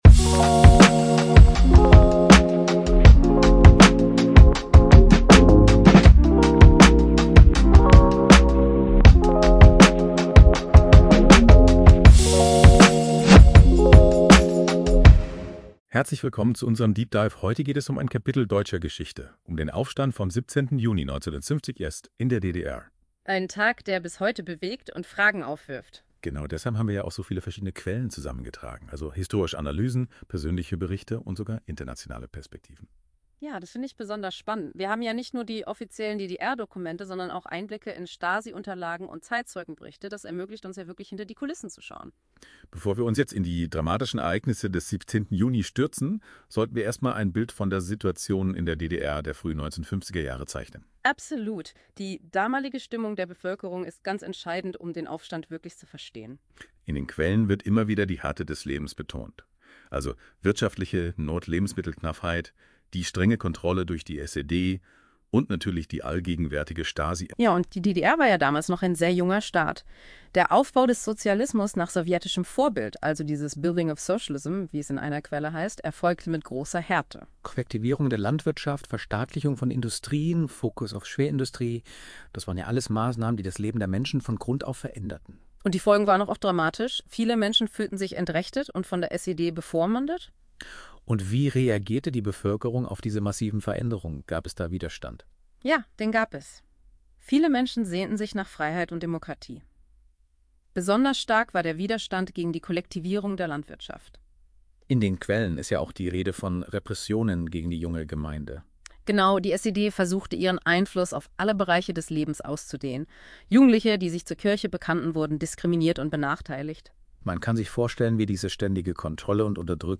Einige Wörter werden amerikanisch ausgesprochen. Außerdem wird das Jahr des 17. Juni aus technischen Gründen nicht immer richtig genannt. Es geht aber immer um den 17. Juni im Jahr 1953.